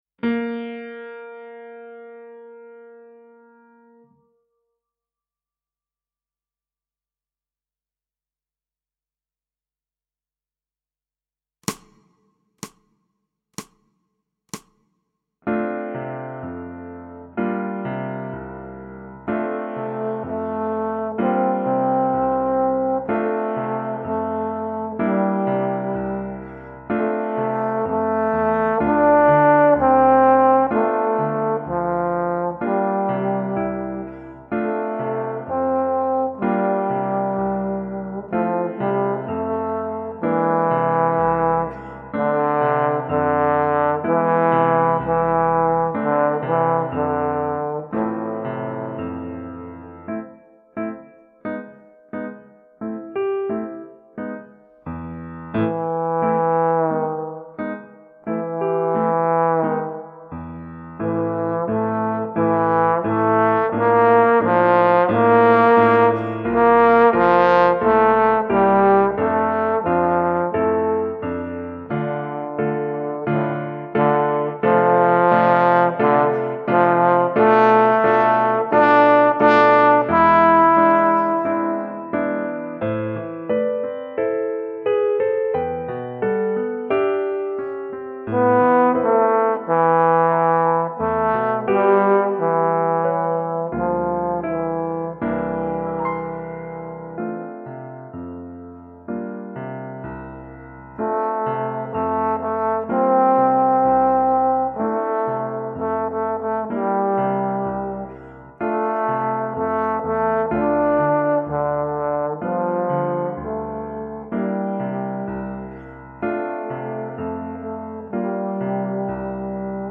Pour trombone et piano DEGRE CYCLE 1 Durée